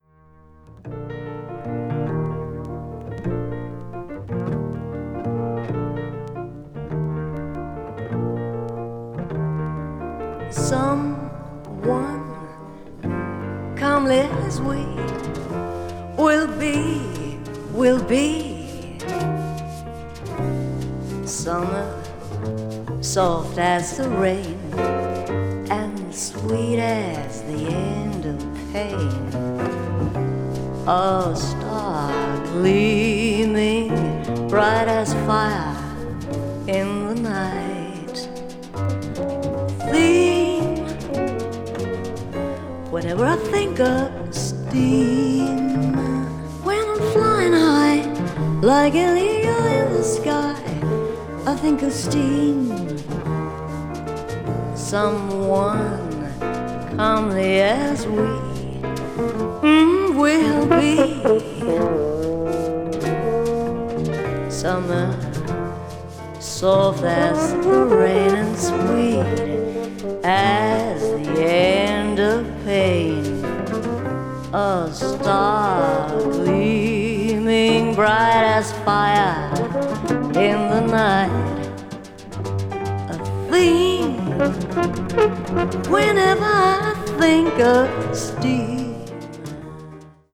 contemporary jazz   jazz vocal   modal jazz   modern jazz